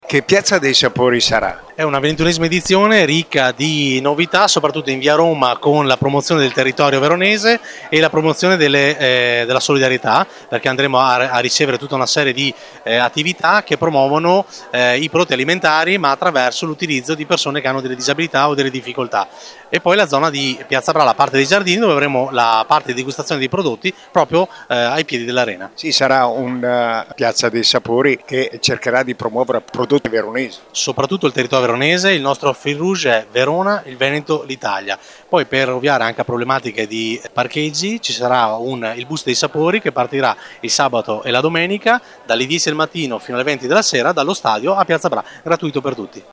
ha raccolto le dichiarazioni nella giornata di presentazione dell’evento: